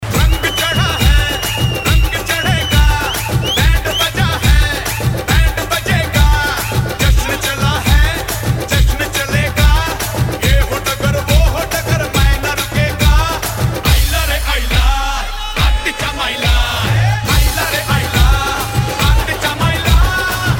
Dandiya Mix Ringtones